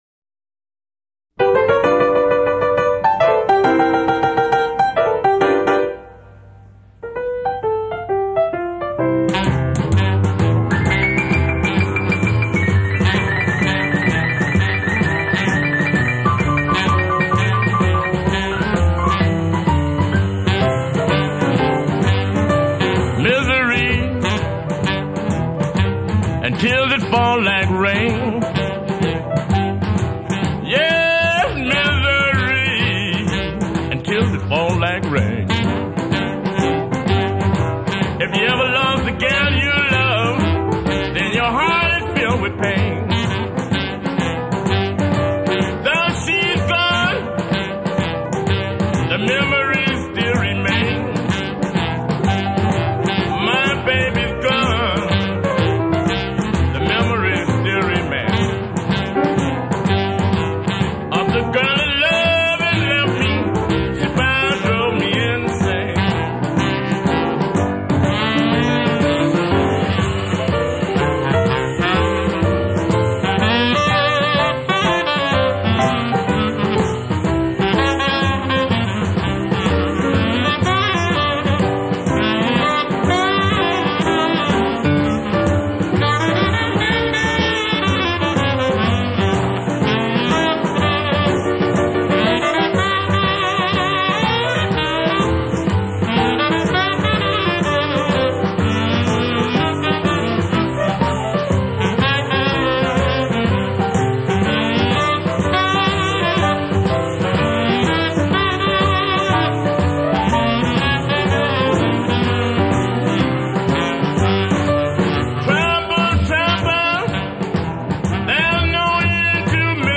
Rhythm & Blues